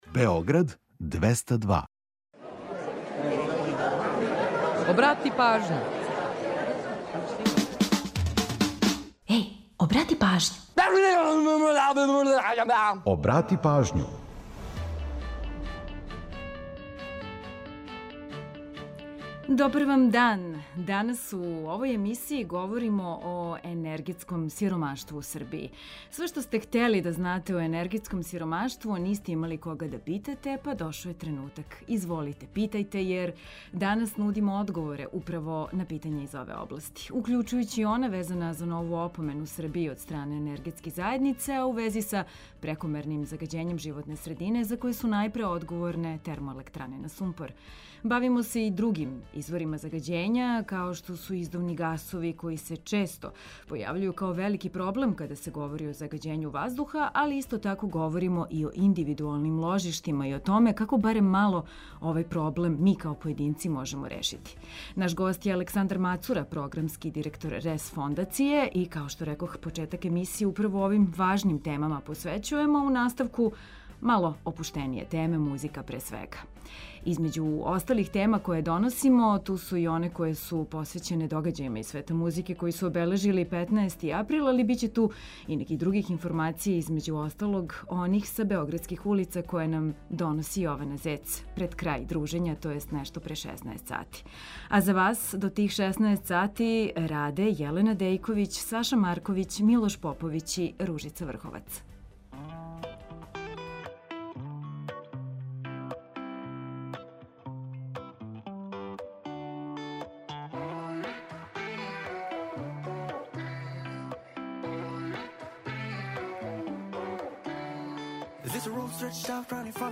У наставку програма очекују вас опуштеније теме, музика, пре свега.